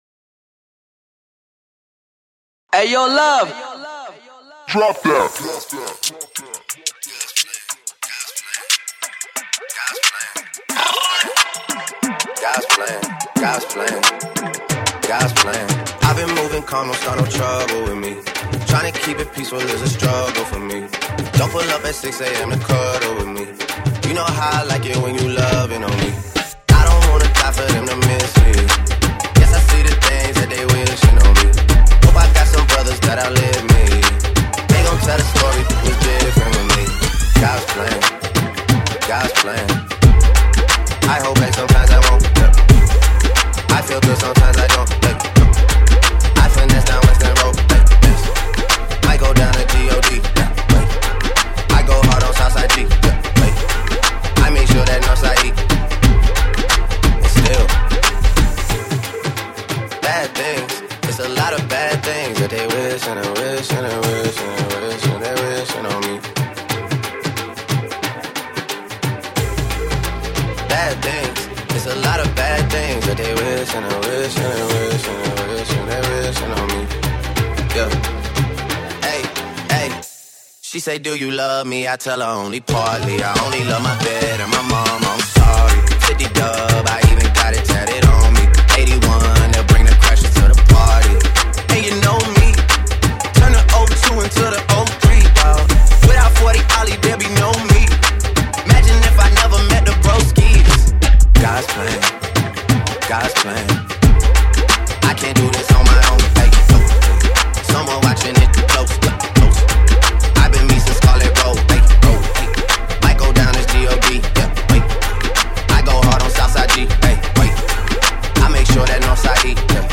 Hip-Hop | Bhangra